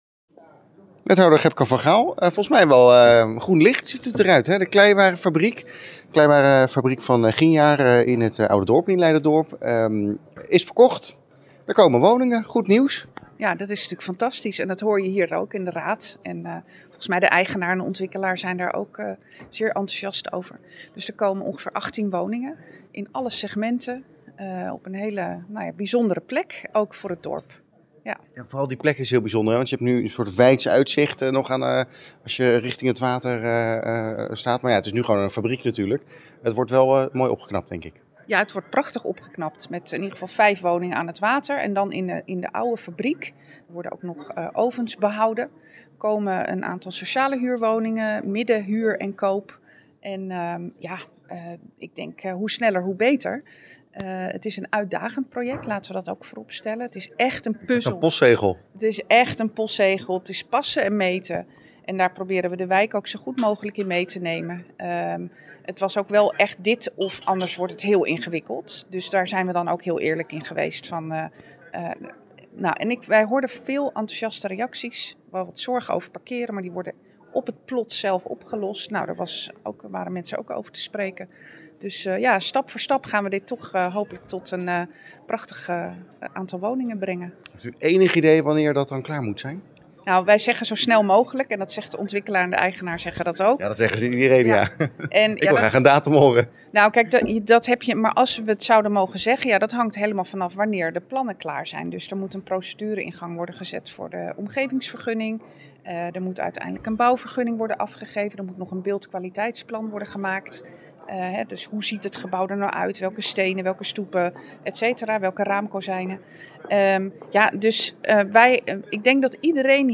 Wethouder Gebke van Gaal over de Kleiwarenfabriek: